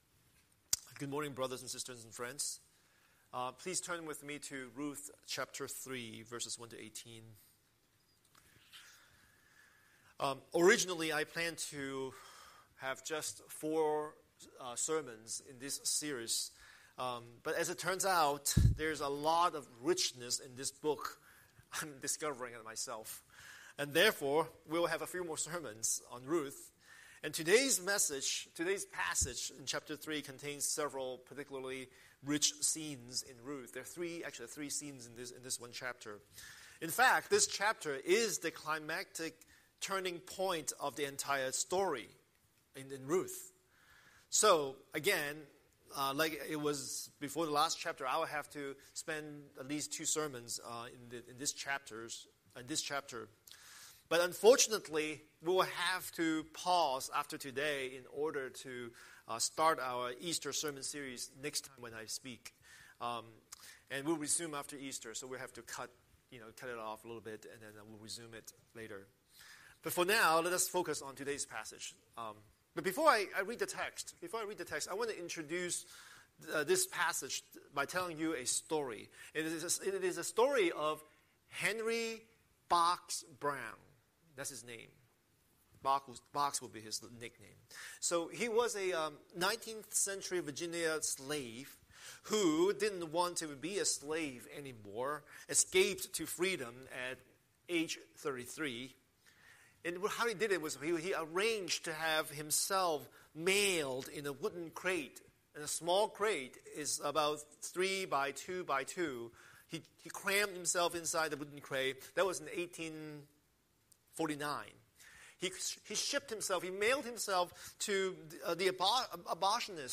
Scripture: Ruth 3:1-18 Series: Sunday Sermon